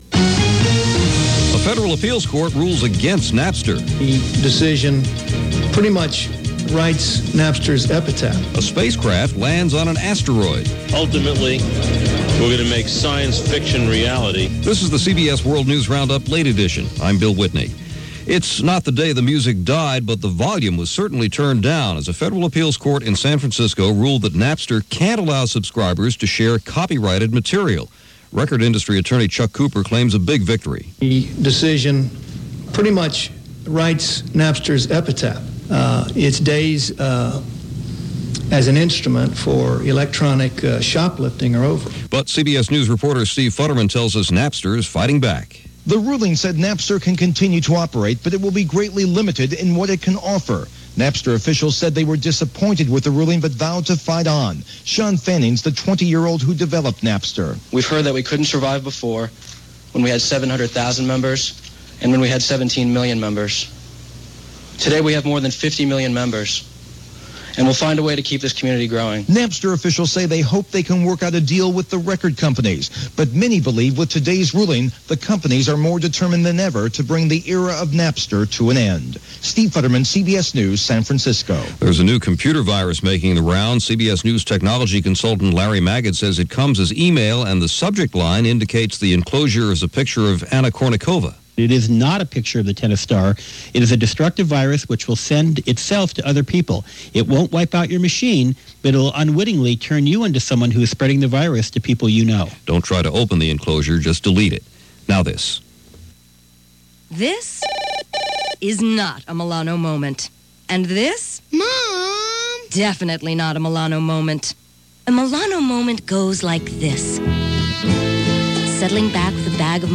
February 12, 2001 – CBS World News Roundup: Late Edition – Gordon Skene Sound Collection –